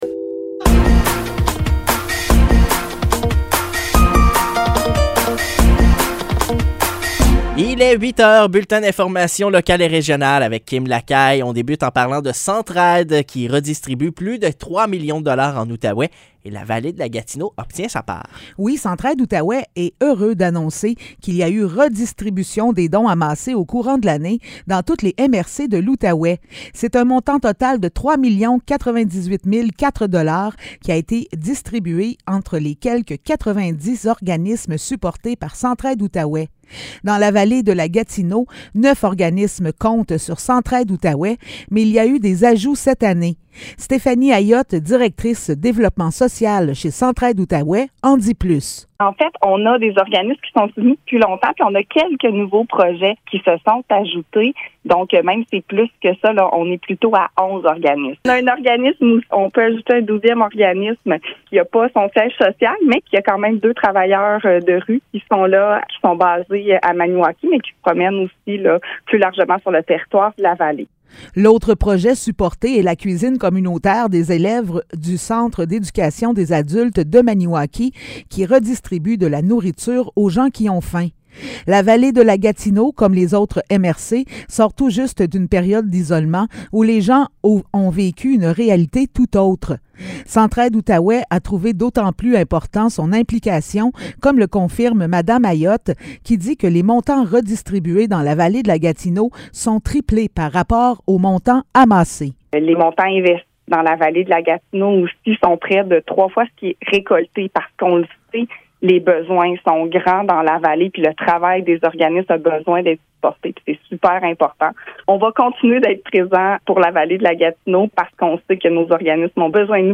Nouvelles locales - 20 juillet 2023 - 8 h